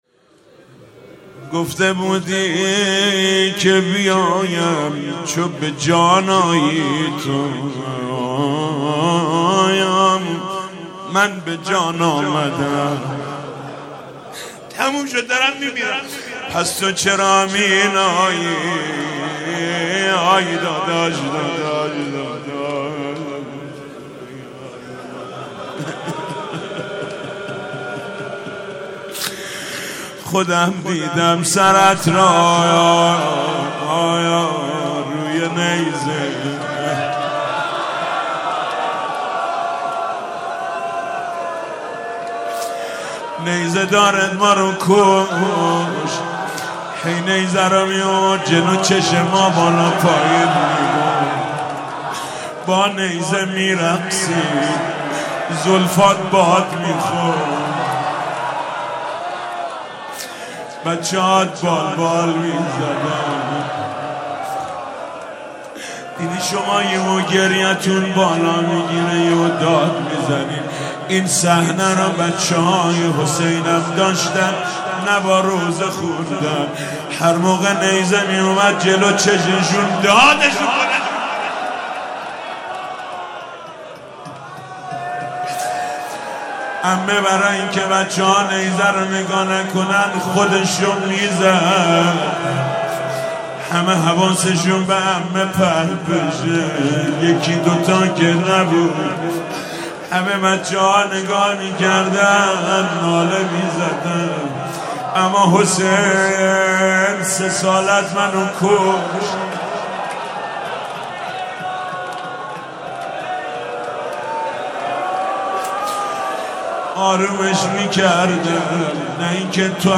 مناسبت : وفات حضرت زینب سلام‌الله‌علیها
مداح : محمود کریمی قالب : روضه